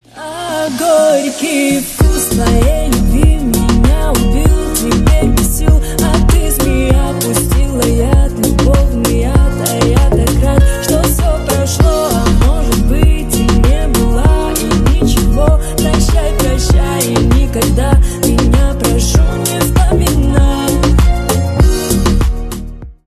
Поп Музыка
грустные # кавер